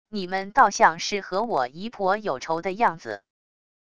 你们倒像是和我姨婆有仇的样子wav音频生成系统WAV Audio Player